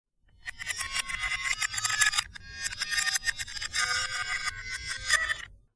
外星人 " 声音外星人1
描述：用Audacity制作的令人难以置信的声音。
标签： 外星人 大胆 电子 文件 外国的 好玩的 有趣的 星系 电脑 机器人 机器人 空间 T 谈话 谈话 飞碟 宇宙 语音
声道立体声